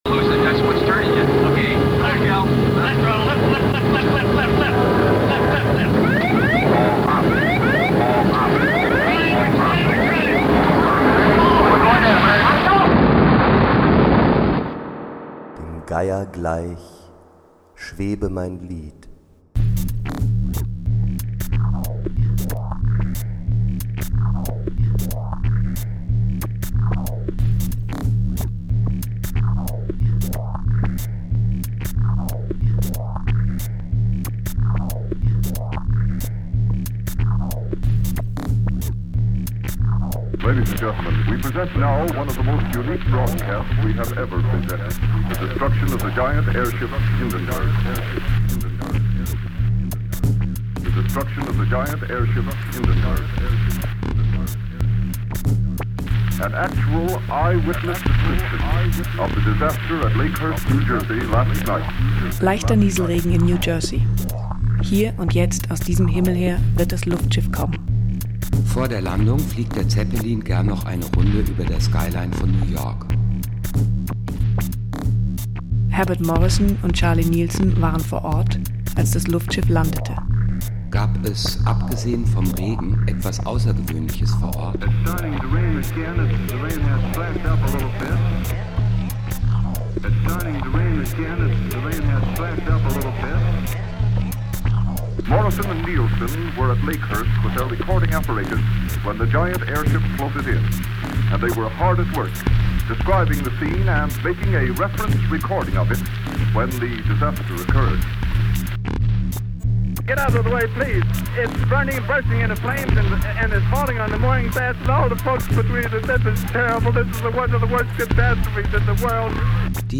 Hindenburg über Lakehurst O-Ton Dance-Track
12 Zeilen Ovid auf Latein
Song aus Cockpit-Warnungen
Wechselgesang